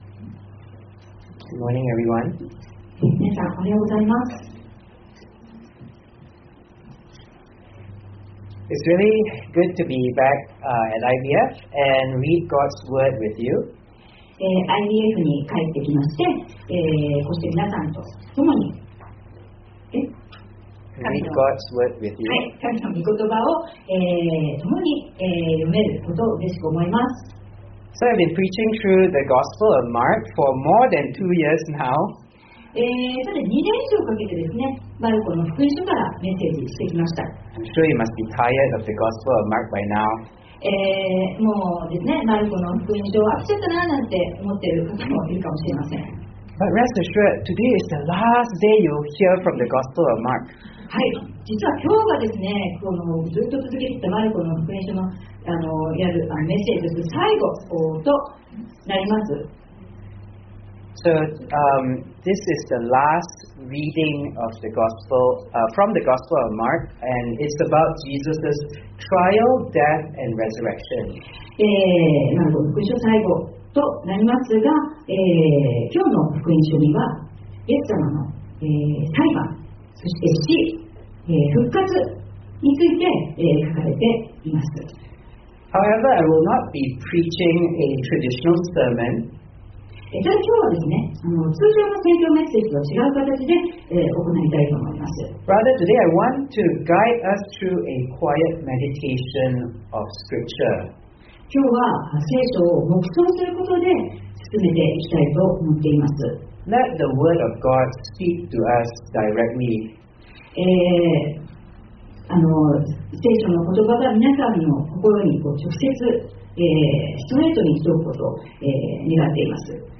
（日曜礼拝録音）【iPhoneで聞けない方はiOSのアップデートをして下さい】十字架の道行（みちゆき）皆さん、おはようございます。